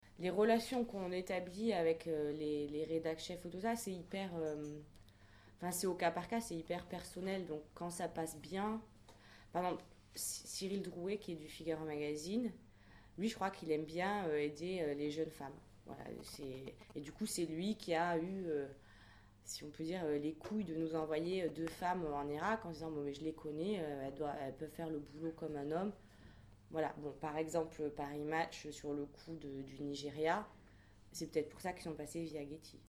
Lors d’une conférence le 12 février à l’Emi devant la promotion photojournalistes 2009, la photographe Véronique de Viguerie a retracé son parcours et parlé avec un détachement naturel de ses reportages dans les zones de tension.